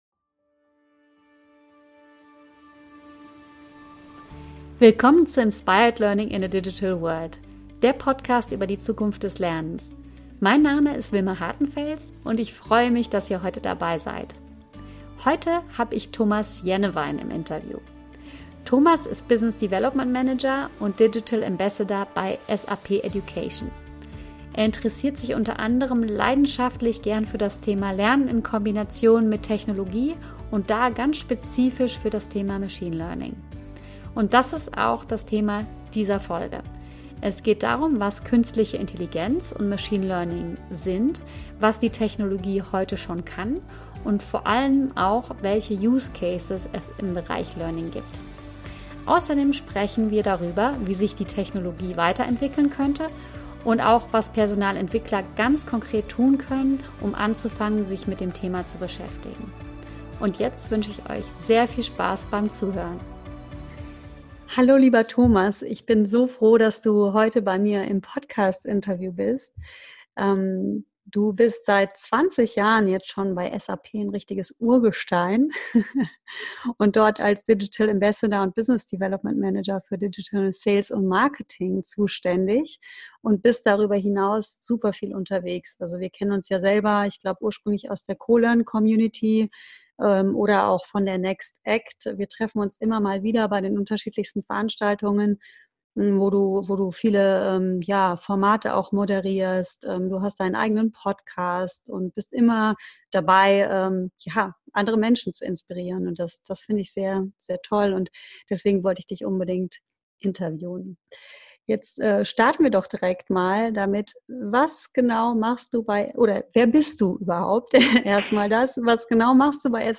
In dieser Folge interviewe ich